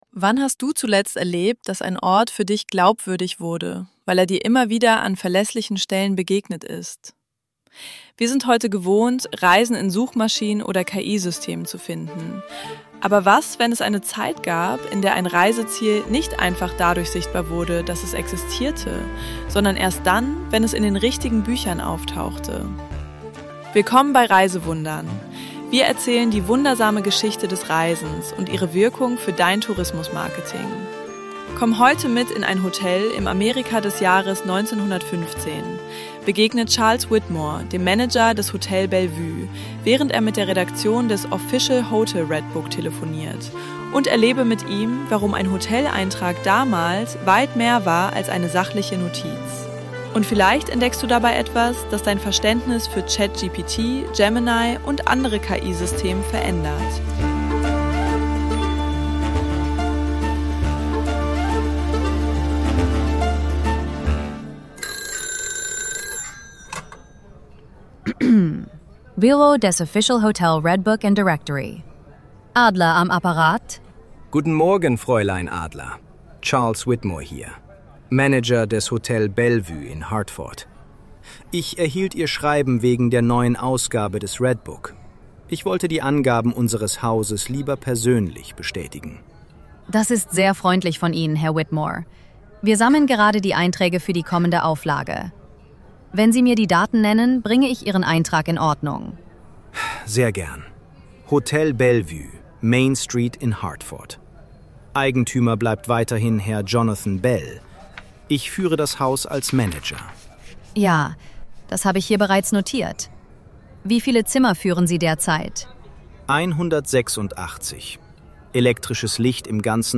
Reisewundern ist ein wöchentliches szenisches Hörspiel für dein Tourismusmarketing.